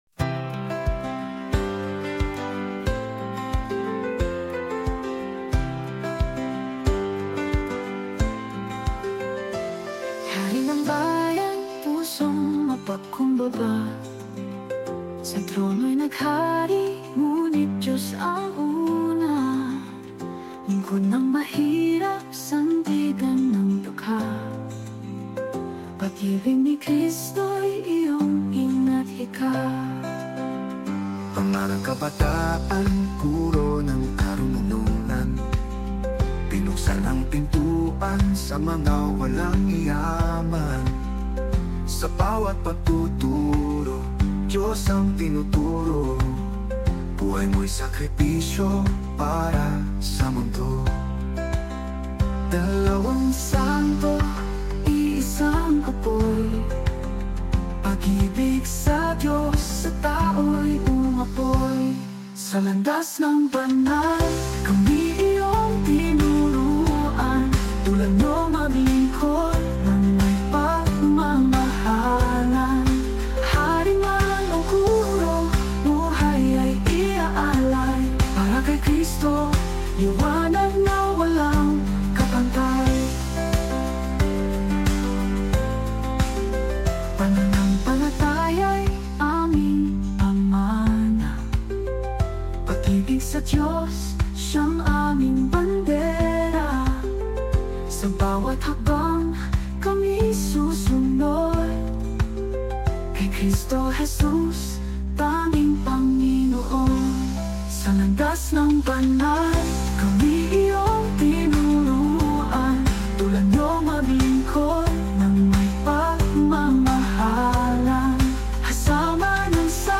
Tagalog worship song